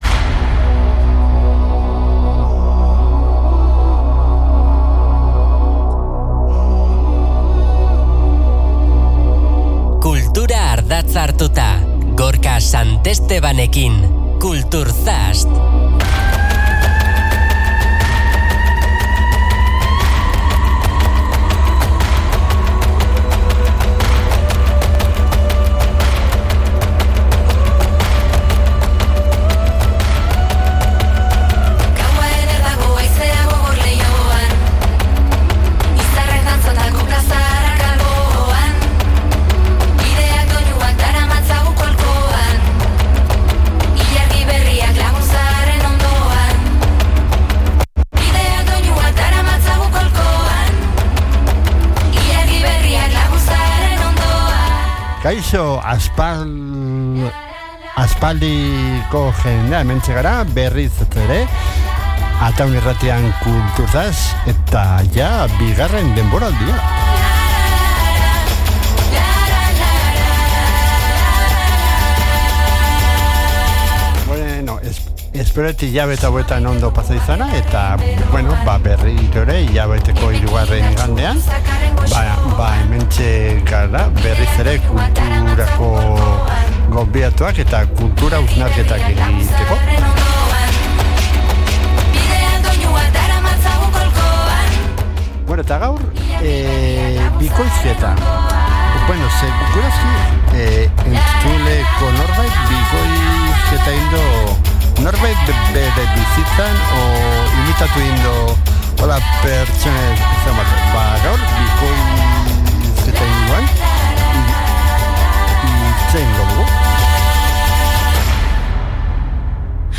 Agendako hitzordu garrantzitsuak, kultur-egileei elkarrizketak eta askoz ere gehiago bilduko ditu ordubeteko tartean.